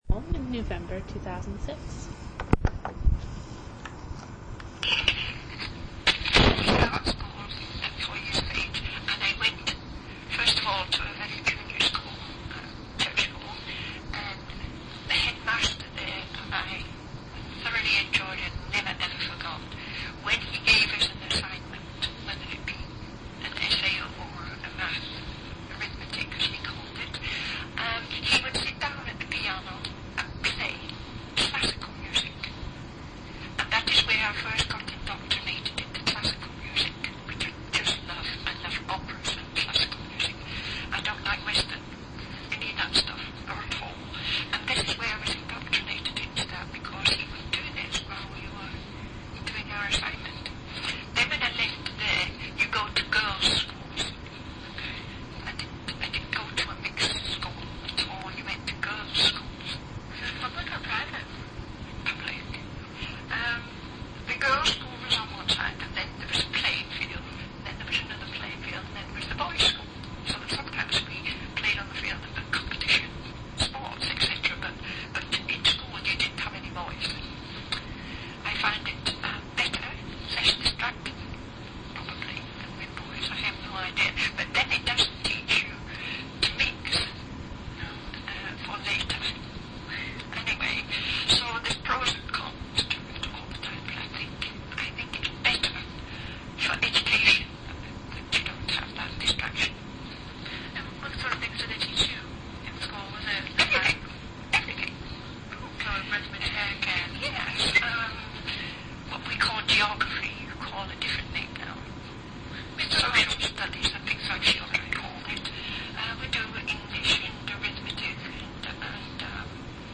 One original audio cassette in Special Collections.